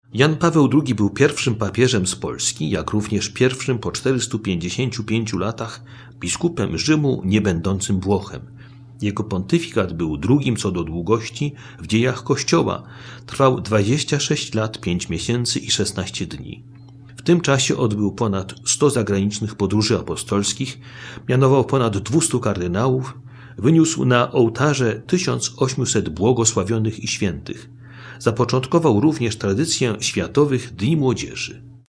„Nie lękajcie się!”, „Otwórzcie drzwi Chrystusowi!” to kulminacyjny punkt homilii, którą Jan Paweł II wygłosił podczas inauguracji swojego pontyfikatu, 22 października 1978 roku.